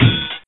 ow.wav